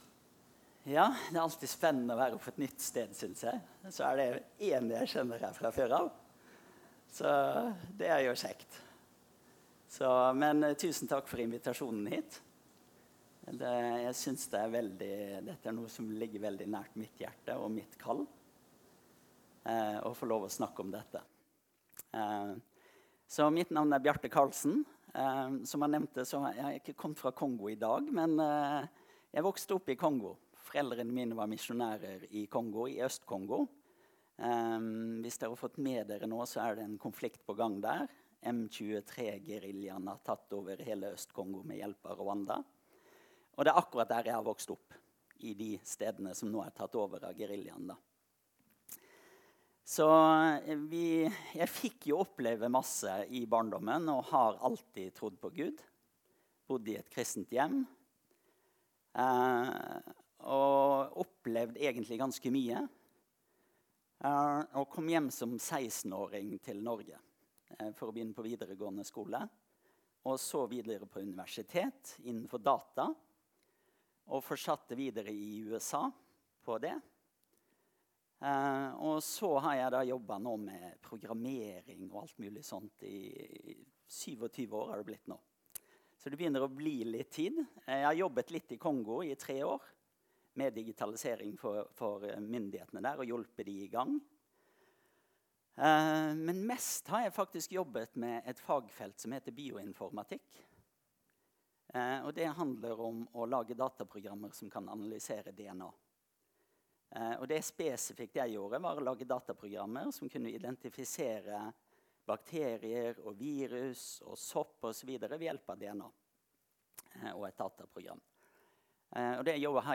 Møte: Seminar